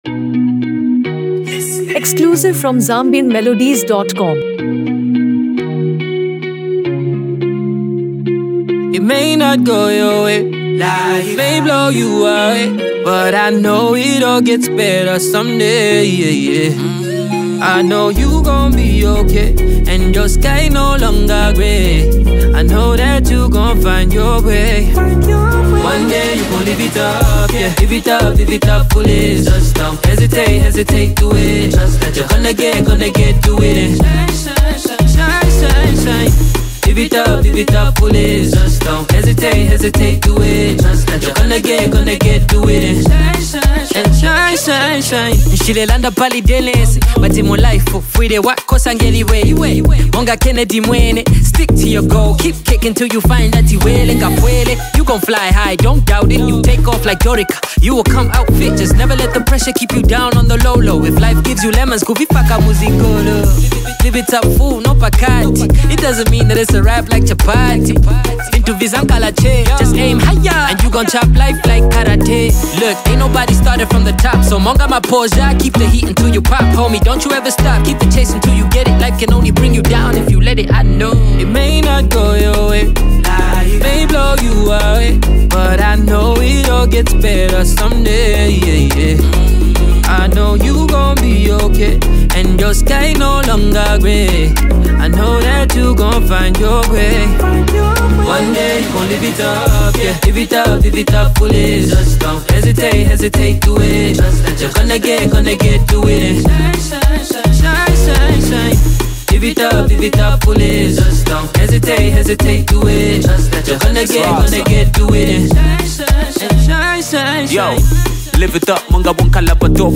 blending Afrobeat with elements of reggae and hip-hop